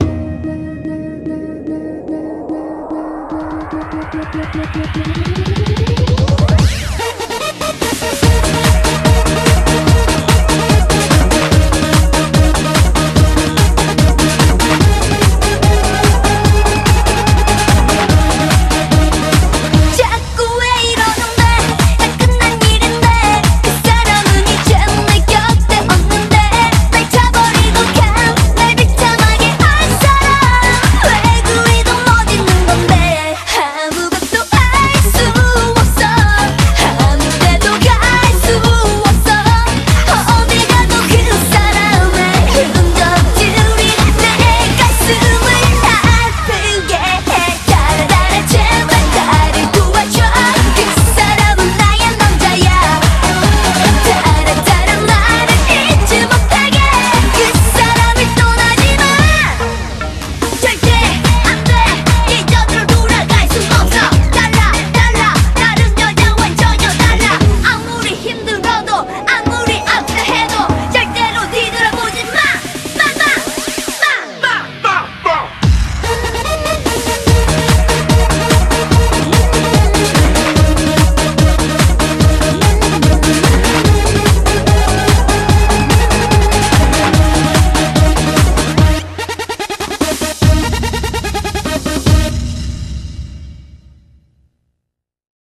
BPM146
MP3 QualityMusic Cut